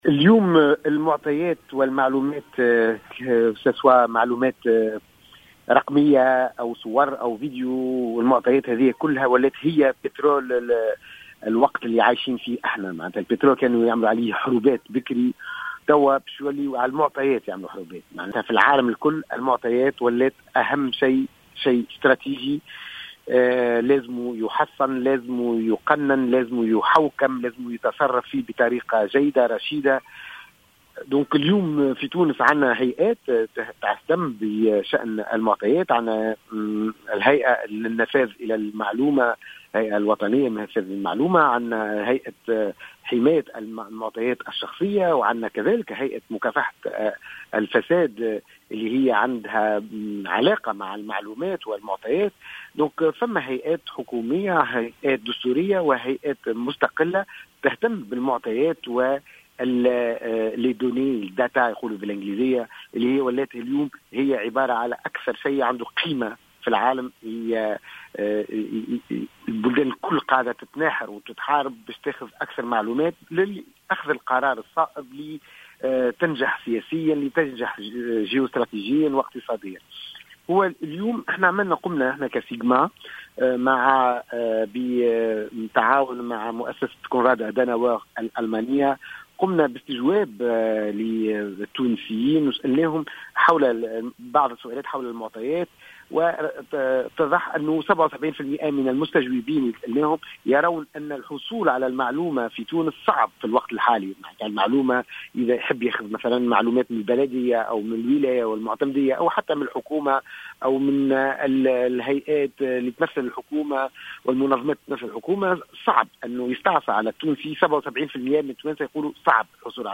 وأكد مدير المؤسسة، حسن الزرقوني، في تصريح للجوهرة أف أم، أن ثلثي المستجوبين يقرون بصعوبة الحصول على حق النفاذ إلى المعلومة، نظرا للتعقيدات التي تحول دون تطبيق آليات نشر المعلومات، خاصة عبر المواقع الإلكترونية الرسمية.